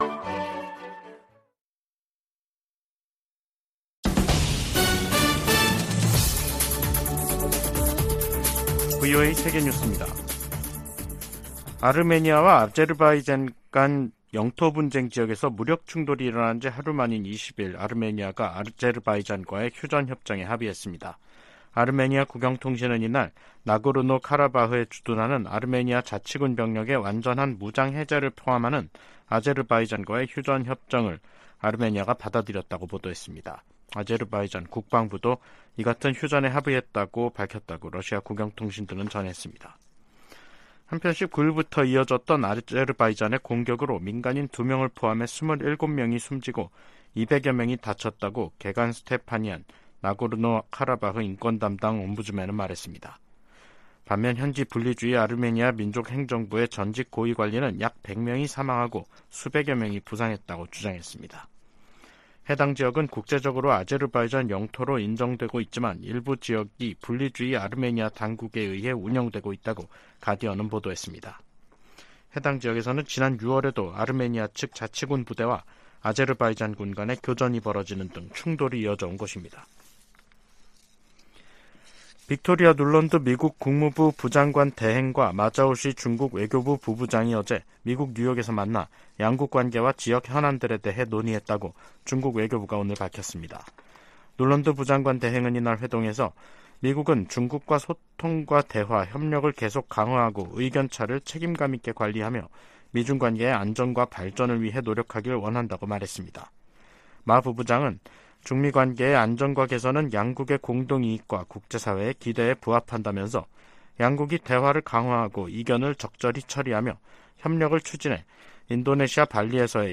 VOA 한국어 간판 뉴스 프로그램 '뉴스 투데이', 2023년 9월 20일 2부 방송입니다. 조 바이든 미국 대통령이 유엔총회 연설에서 북한 정권의 거듭된 유엔 안보리 결의 위반을 규탄했습니다. 한국 정부가 러시아 대사를 초치해 북한과의 군사 협력 움직임에 대해 강력히 경고했습니다. 러시아가 북한 같은 나라에 의존할 수밖에 없을 정도로 고립됐다고 로이드 오스틴 미 국방장관이 지적했습니다.